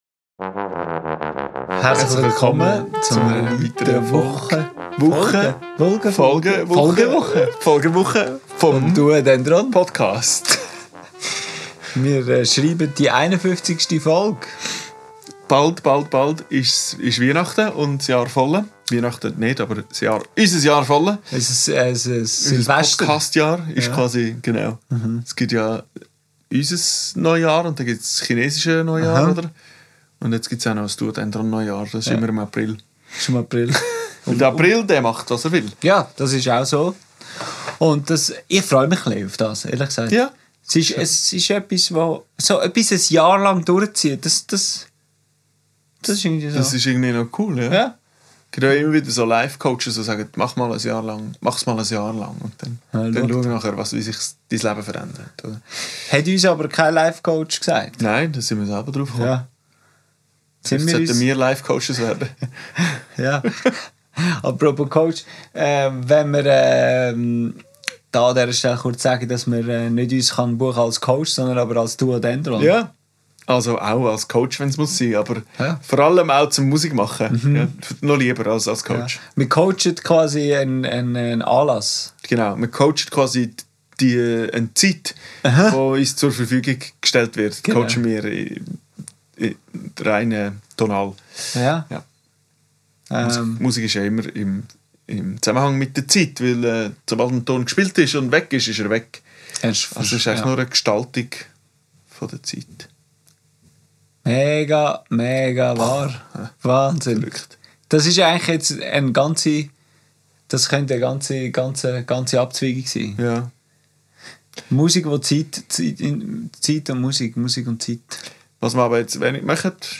Aufgenommen am 21.02.2025 im Atelier